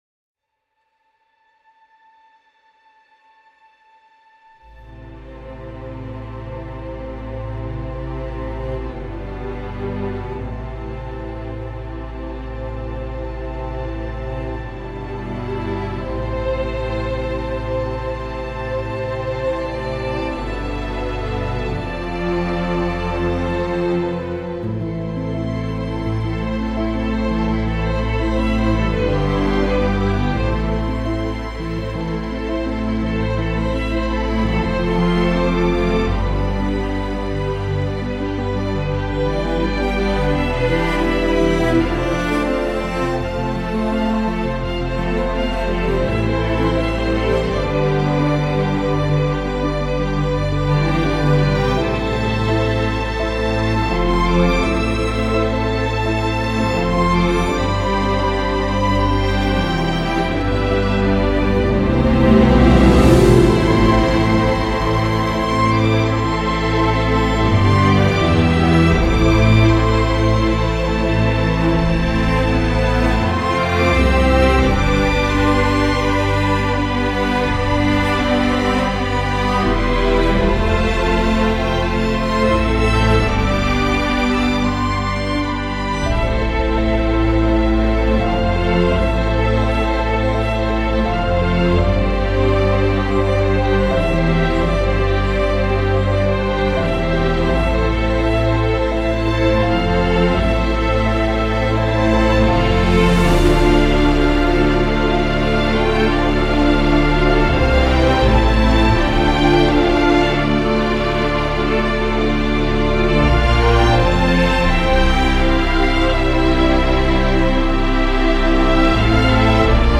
partition romantique pastorale
violon